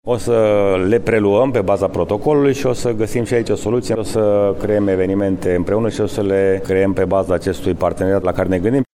George Scripcaru, primar municipiul Brașov: